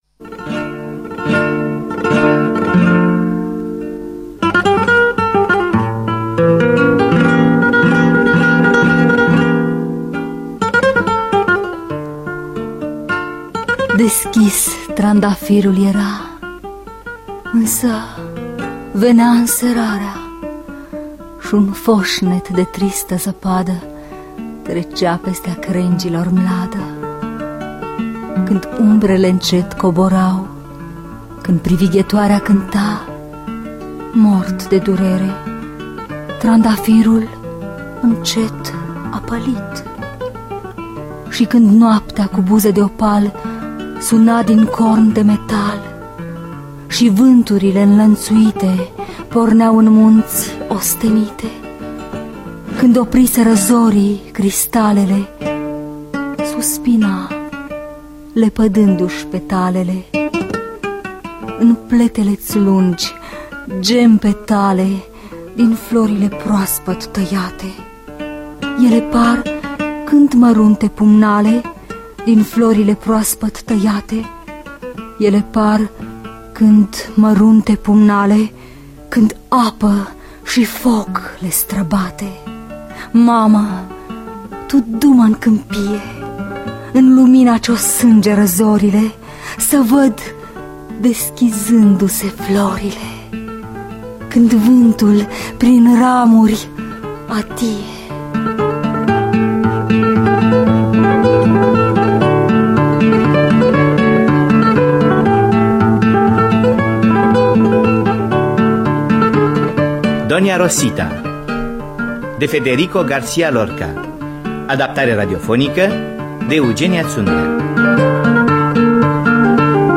Adaptare radiofonică